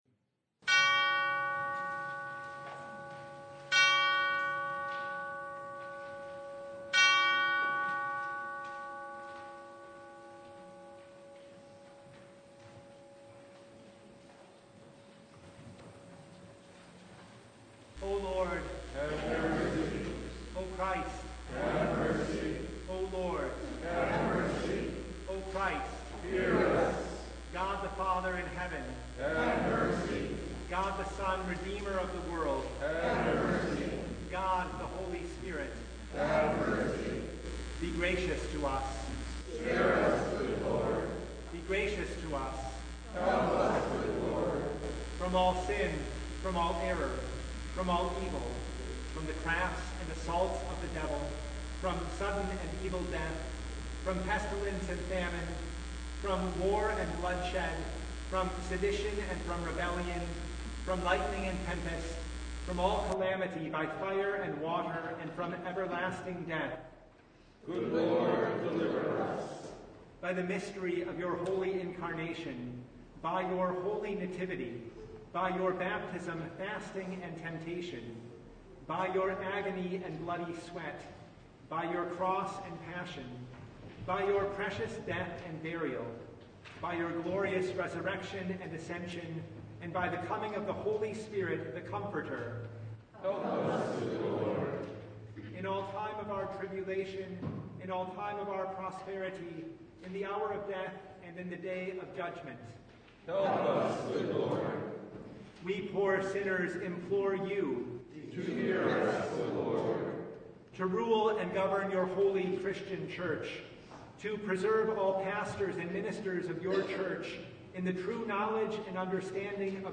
Service Type: Lent Midweek Noon
Full Service